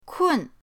kun4.mp3